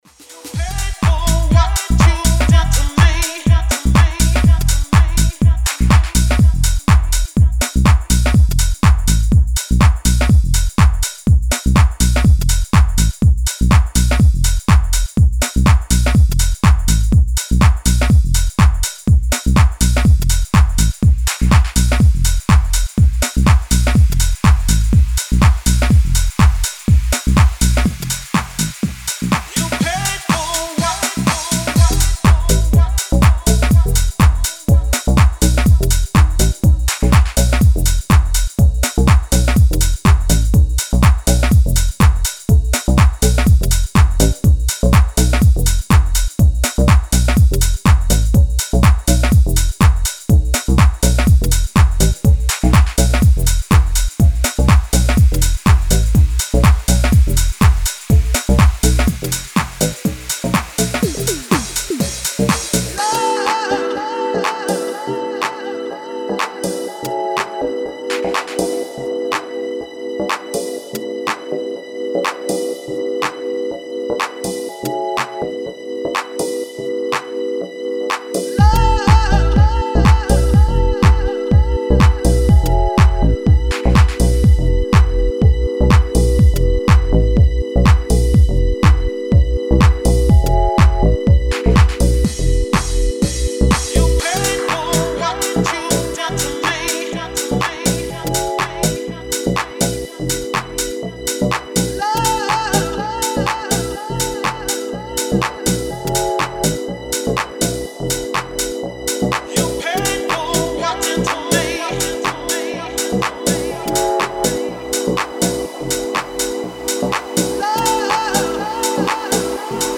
two real house cuts !
Style: House